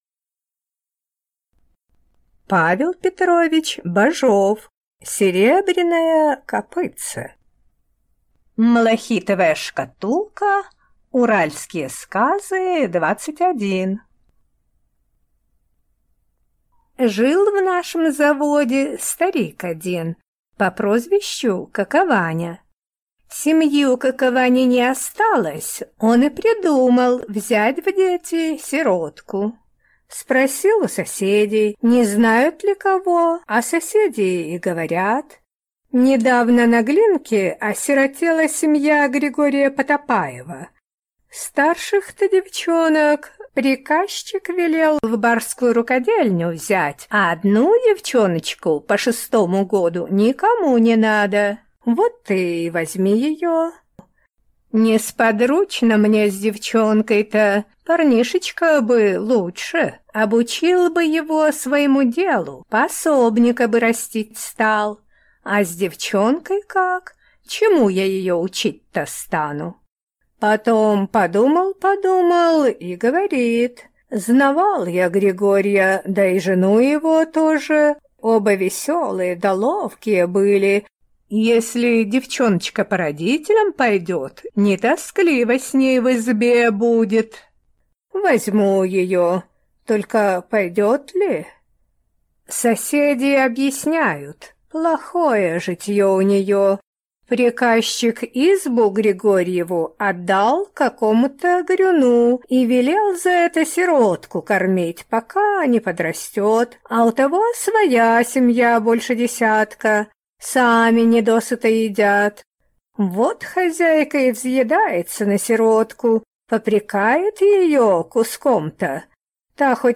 Аудиокнига Серебряное копытце | Библиотека аудиокниг